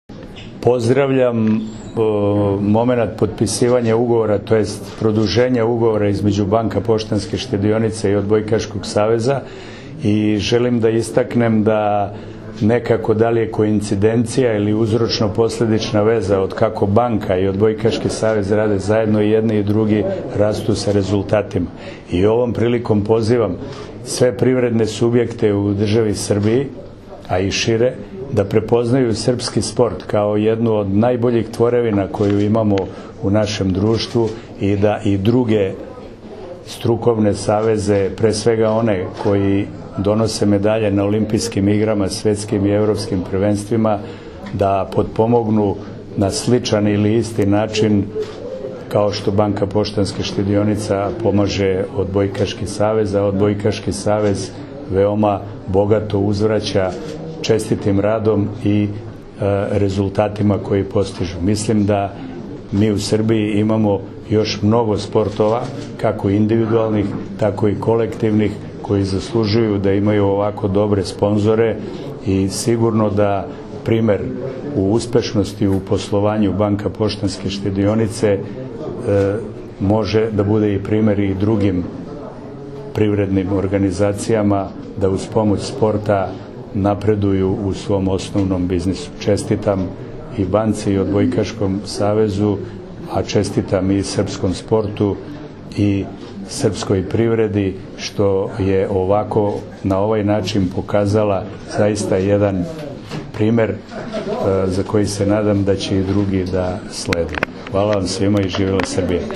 Svečanoj ceremoniji su prisustvovali Zoran Gajić, ministar sporta u Vladi Republike Srbije, članovi Upravnog i Izvršnog odbora Banke Poštanska štedionica, kao i delegacija Odbojkaškog saveza Srbije.
Izjava Zorana Gajića